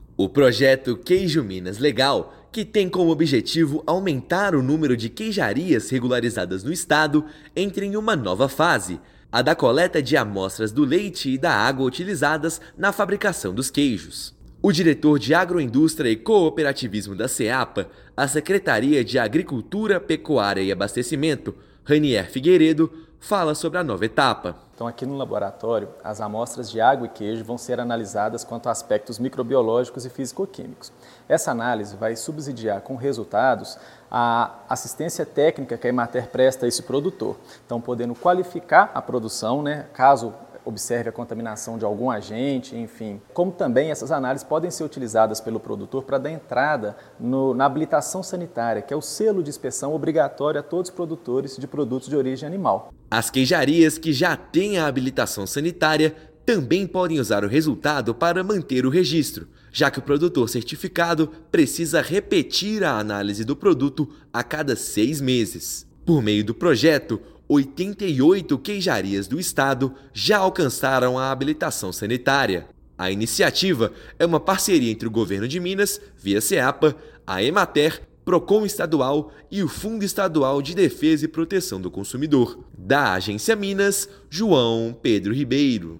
Para aprimorar os processos e ampliar a qualidade dos produtos, estão sendo coletadas agora amostras de leite e da água utilizados na fabricação dos queijos. Ouça matéria de rádio.